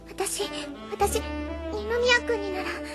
本編から拾った「や」の音声を比べてみます。